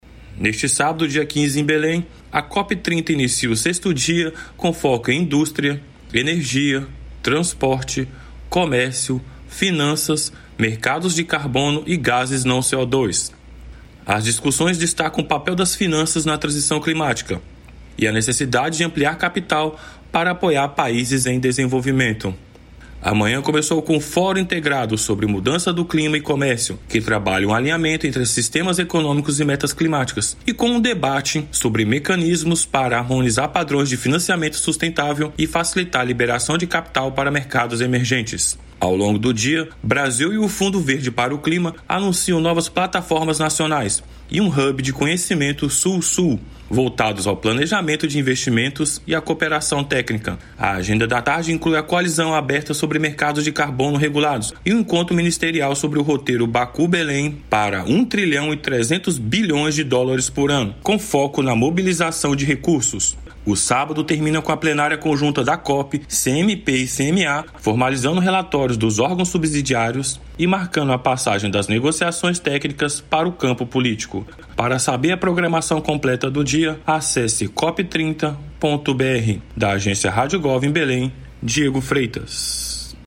08/04/20 - Pronunciamento do presidente Jair Bolsonaro